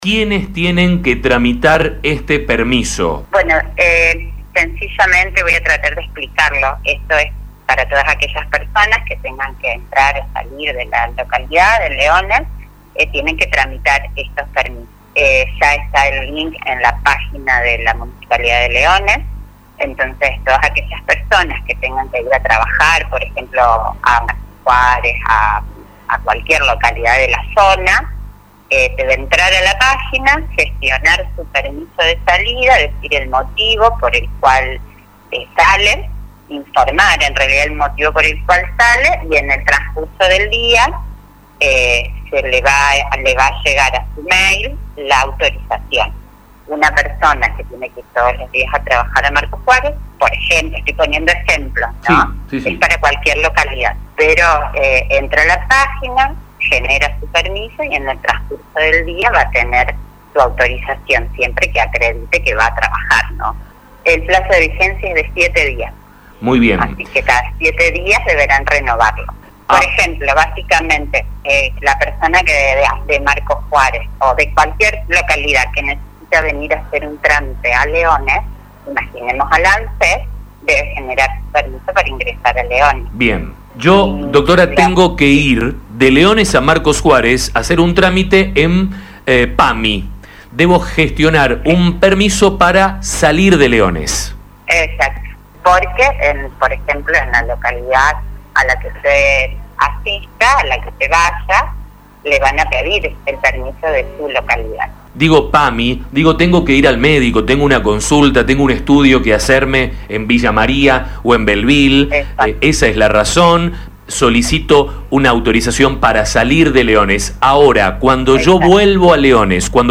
En diálogo con La Mañana, la secretaria de gobierno, Ingrid Grasso, respondió varias consultas.